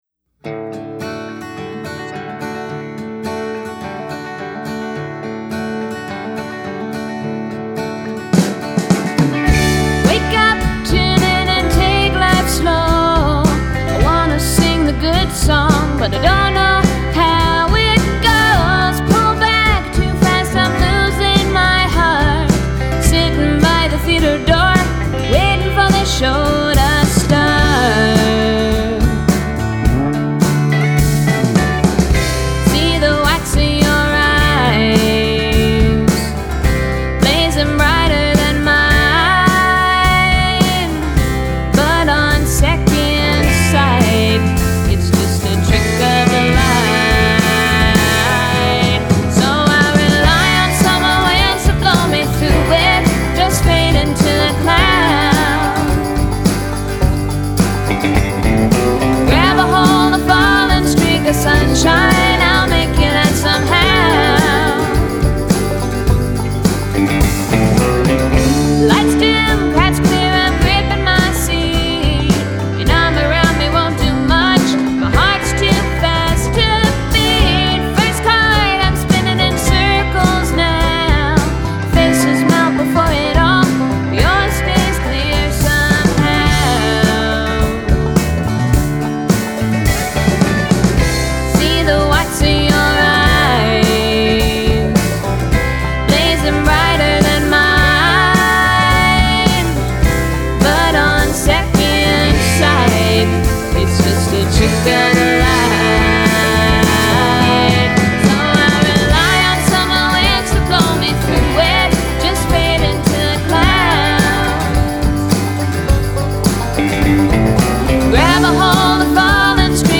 Recorded at The Lincoln County Social Club.
Vocals, acoustic Guitar
drums, tambourine, shaky skull.